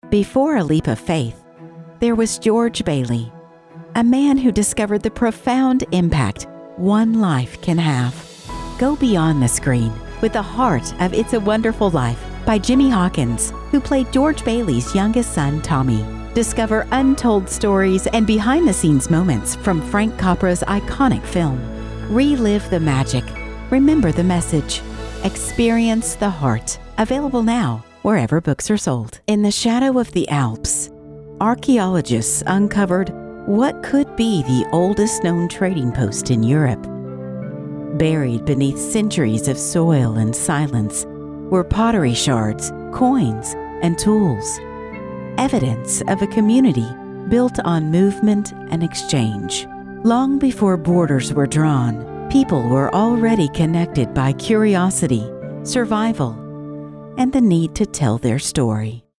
Female Voice Over Talent
Brigtht, Natural, Touch of the South.
Narration